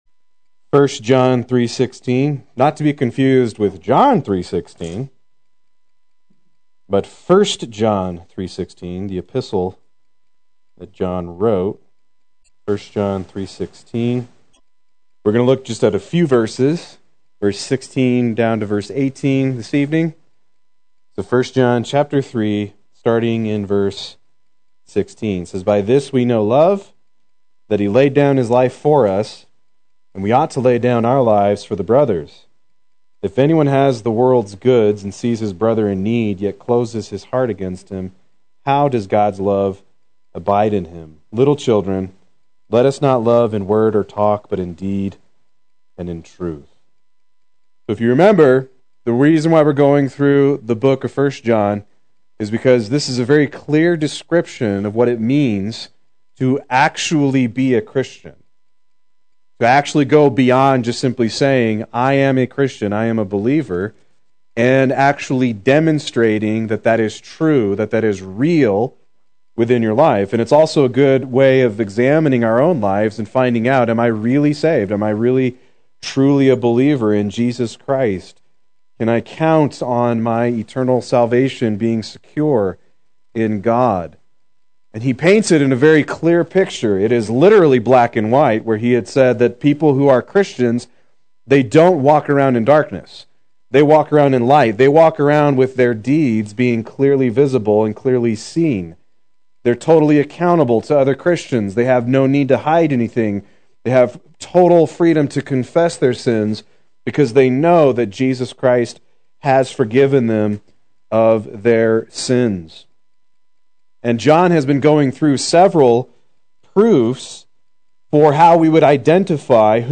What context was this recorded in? Proclaim Youth Ministry - 04/26/19